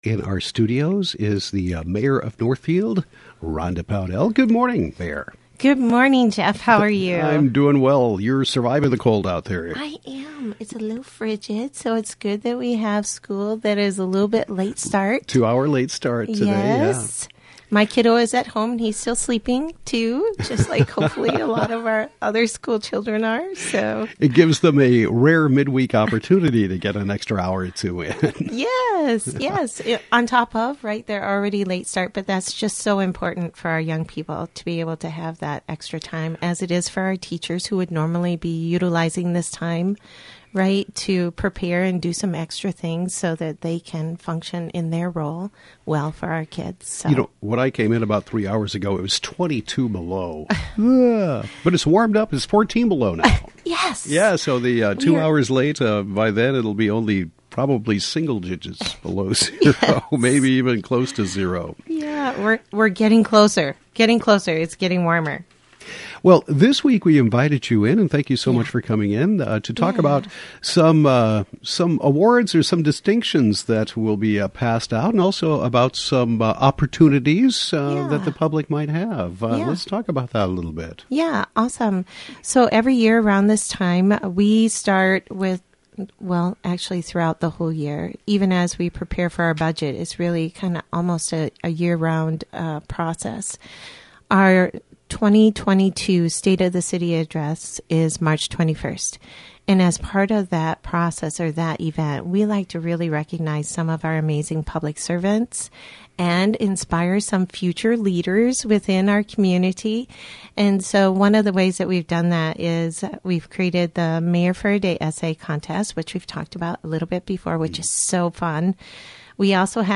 Northfield Mayor Rhonda Pownell talks about the various awards and distinctions that will be announced at the March 21 State of the City address, the Mayor for a Day Essay contest, opportunities for citizens to participate on city boards and commissions, and more.